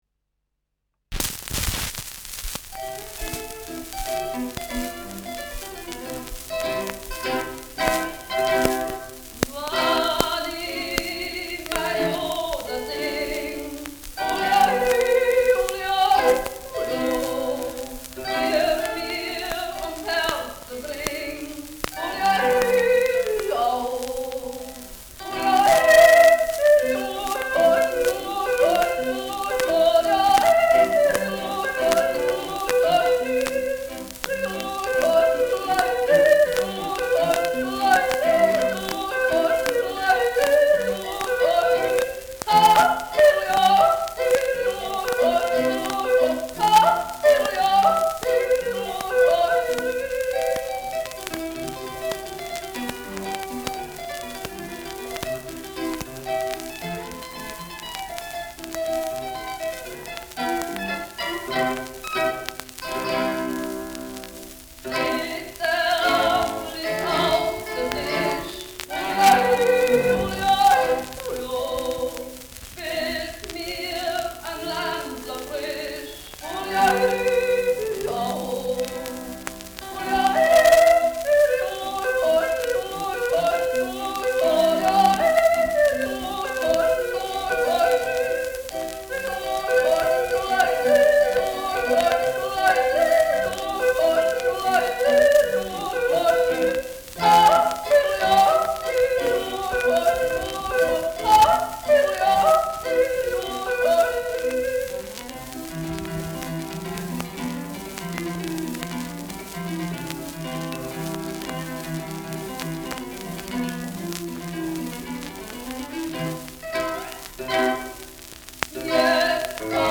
Schellackplatte
leichtes Knistern
Ignaz Eisele Truppe, Garmisch (Interpretation)
[München] (Aufnahmeort)